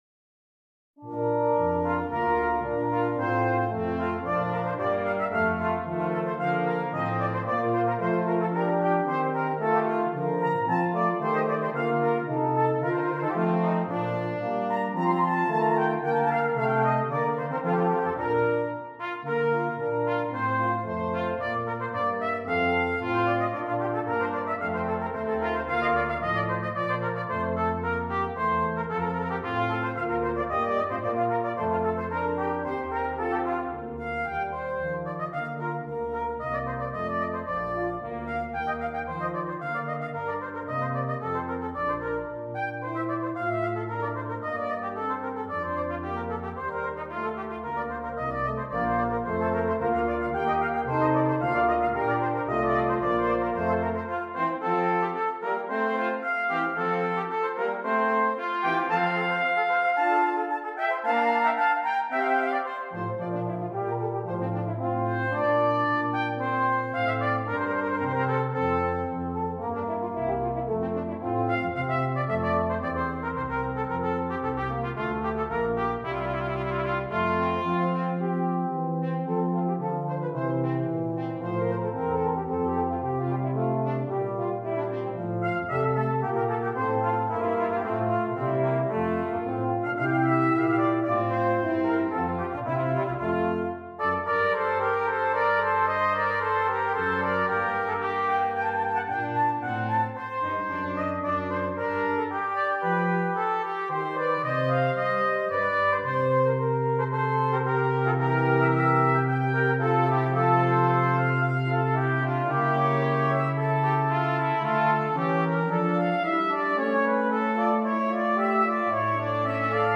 Brass Quintet
This soprano aria has been adapted to feature a trumpet.